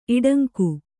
♪ iḍaŋku